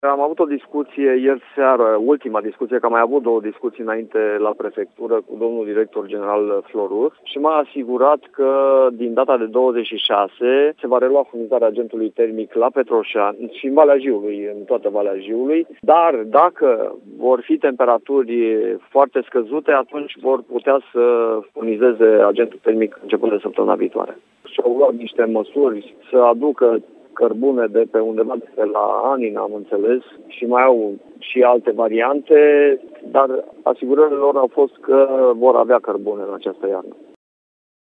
Primarul municipiului, Tiberiu Iacob Ridzi, spune că a primit asigurări ca din data de 26 octombrie se va relua furnizarea agentului termic în întreaga zonă din Valea Jiului.
03.-Tiberiu-Iacob-Ridzi.mp3